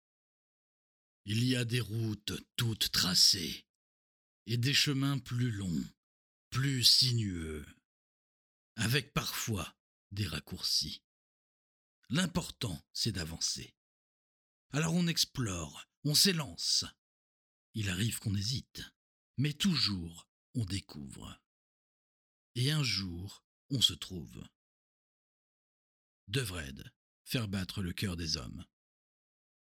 Audition Devred
25 - 50 ans - Baryton